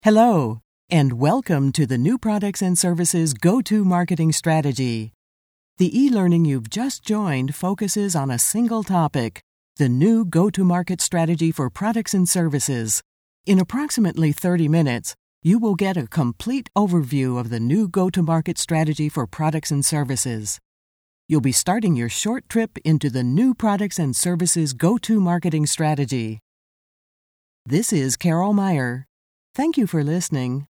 Sennheiser 416 mic, Blue Robbie preamp, Mogami cabling, ProTools 8.04 with various additional plug-ins, MBox mini, Adobe Audition, music and sound fx library.
Mature, deep, sophisticated, narrator, history, biography, arts, nature wildlife programs for film, television, internet. Smooth, classy, believable.
Sprechprobe: eLearning (Muttersprache):